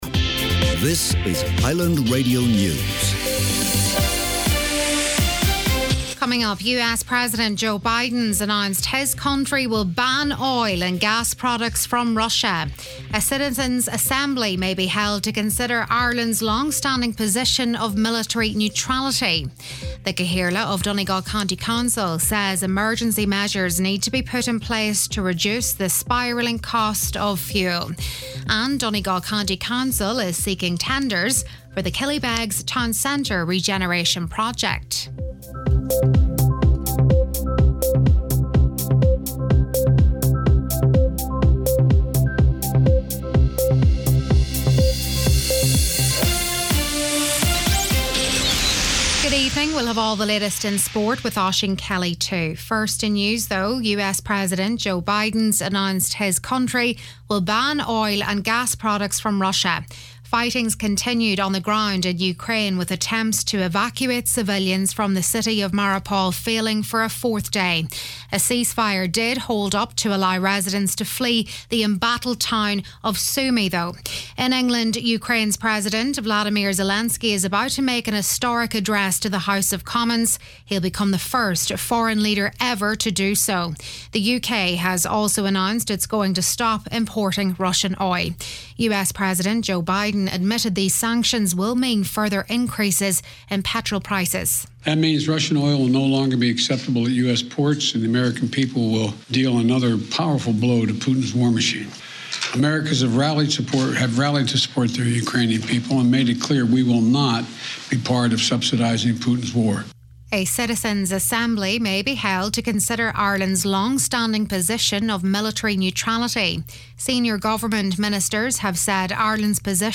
Listen back to main evening news, sport & obituaries